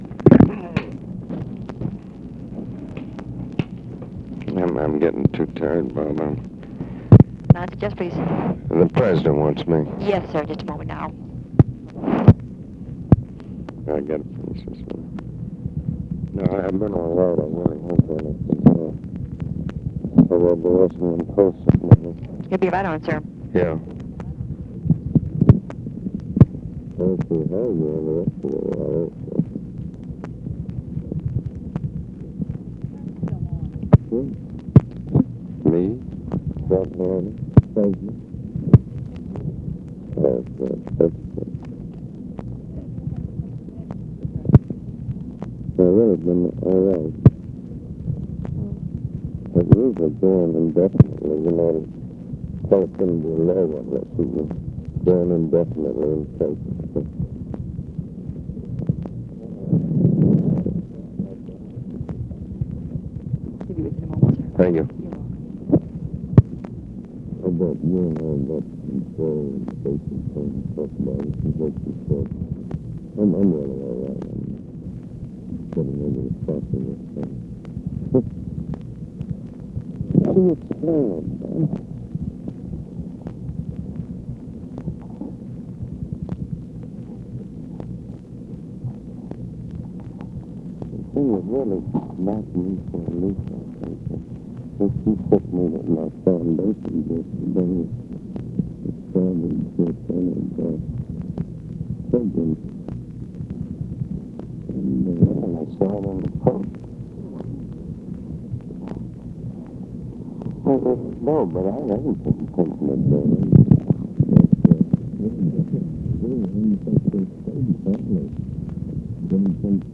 FORTAS ASKS TELEPHONE OPERATOR TO PLACE CALL TO LBJ; INAUDIBLE OFFICE CONVERSATION WITH "BOB" (MCNAMARA?) WHILE FORTAS IS ON HOLD